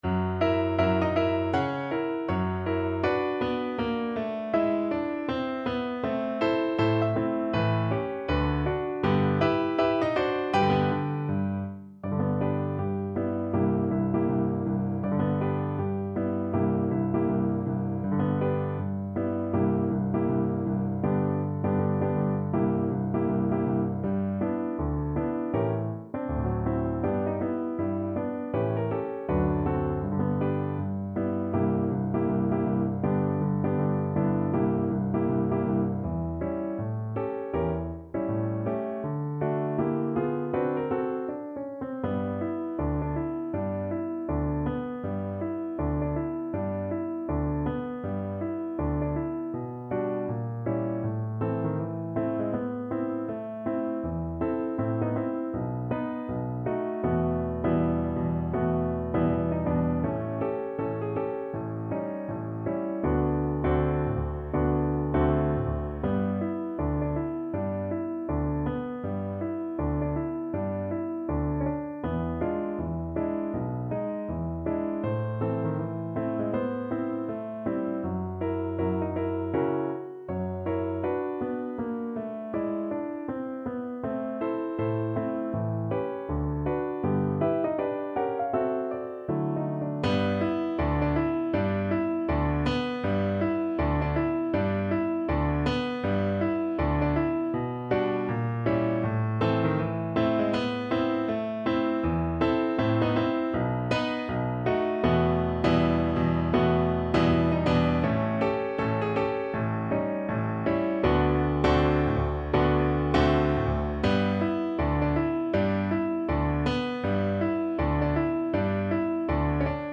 ~ = 160 Moderato
Jazz (View more Jazz Cello Music)